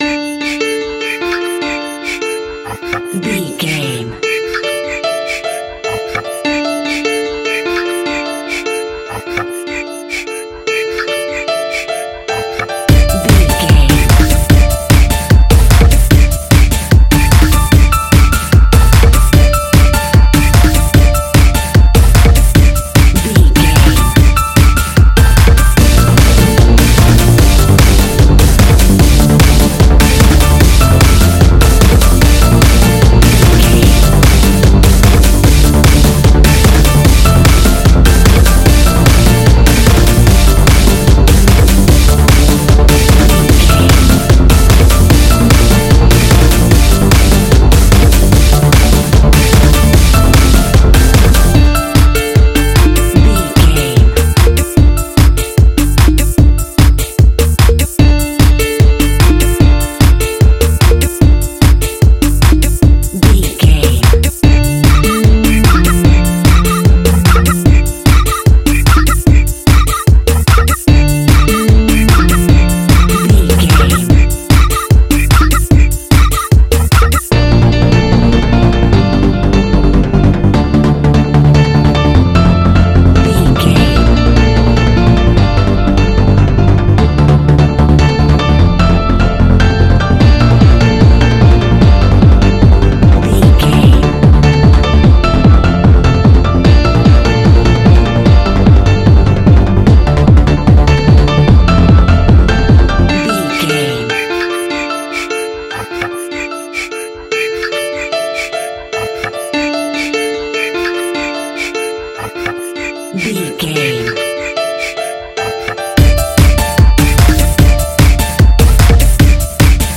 Aeolian/Minor
D
Fast
futuristic
energetic
uplifting
hypnotic
industrial
drum machine
piano
synthesiser
percussion
acid house
electronic
uptempo
synth leads
synth bass